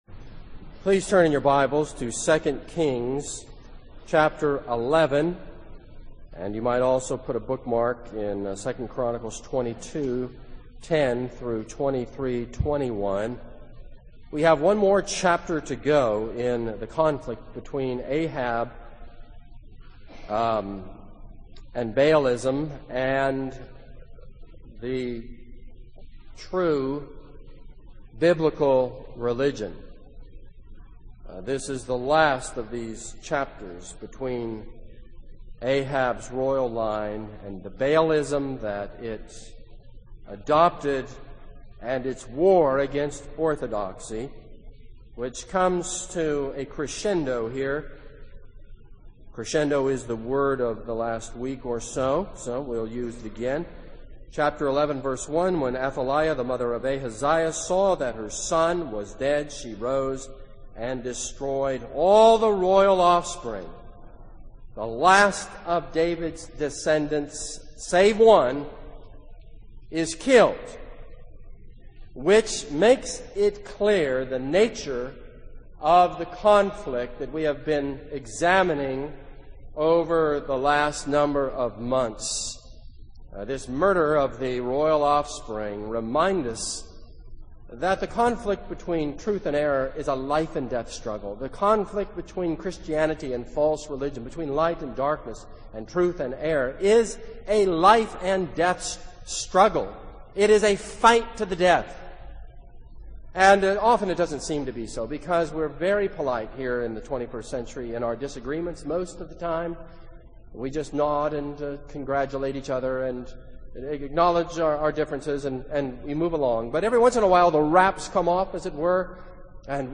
This is a sermon on 2 Kings 11.